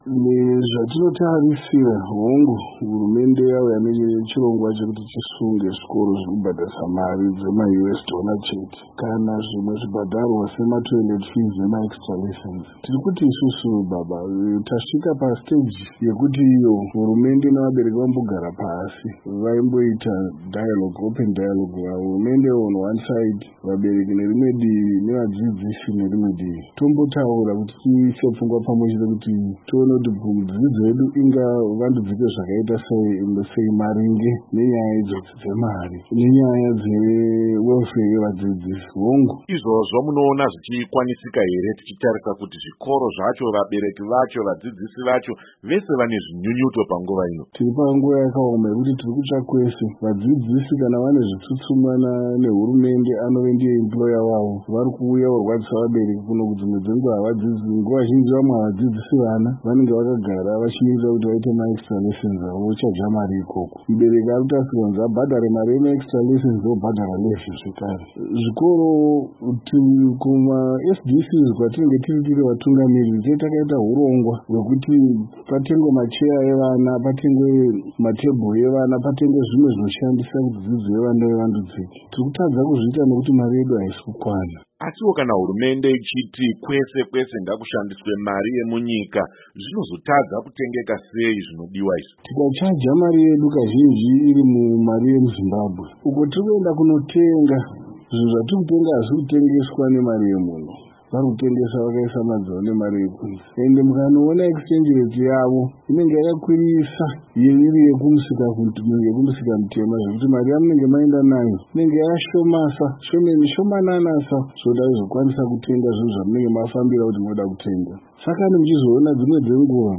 Hurukuro